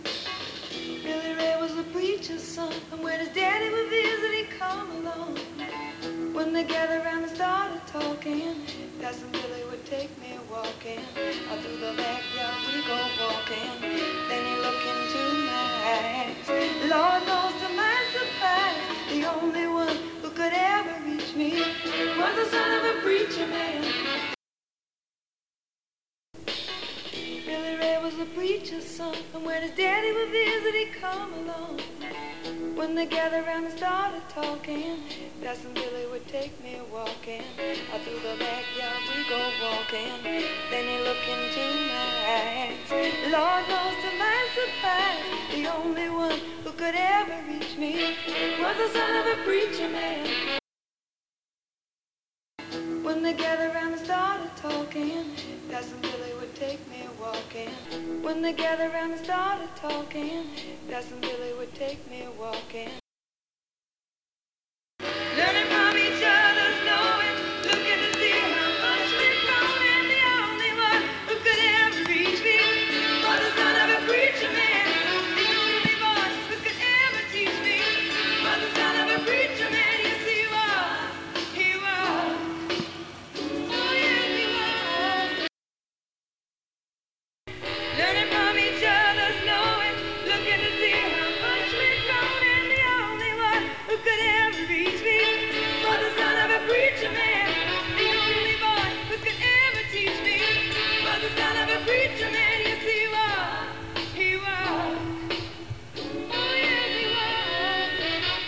För att verifiera problemet ställde jag upp en mp3-spelare med portabla högtalare på låg volym i ett ganska stort utrymme utanför kontoret.
Avståndet till högtalarna är kanske 1,2 m och volymen är ganska låg (men behaglig i en tyst miljö.)
I nedanstående mp3 har jag justerat upp ljudet från den interna mikrofonen 6 dB för att kompensera för att ljudet blir högre med Rödemikrofonen.
Sektionerna åtskiljs med 2 sekunders tystnad (markeras med "-" nedan).
Intern mic (7D) - Röde SVM - Intern/Röde (korta utsnitt) - Intern mic med IS på och zoomrörelser - Röde med IS på och zoomrörelser.
Det knattrande ljudet från bildstabilisatorn försvinner nästan helt med en Röde SVM i blixtskon, även om lite av zoomljudet finns kvar.